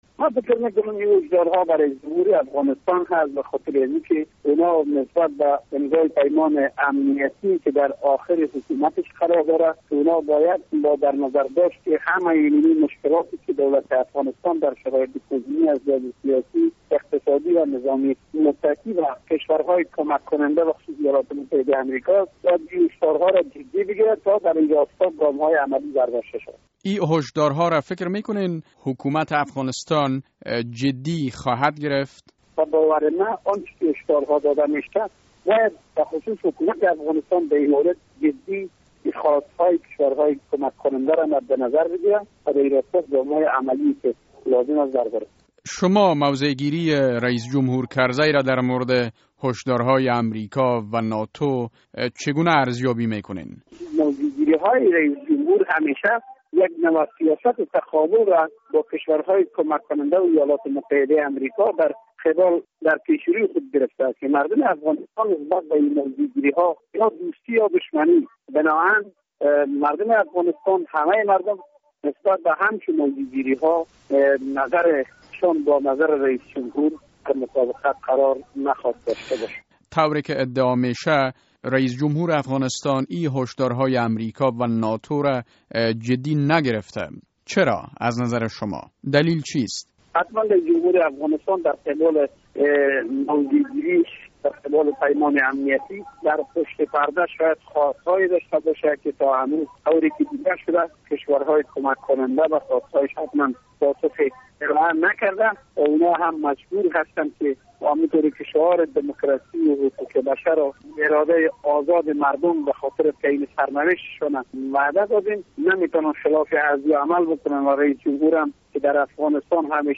مصاحبه: هشدار ناتو در مورد امضاء نشدن سند امنیتی میان کابل و واشنگتن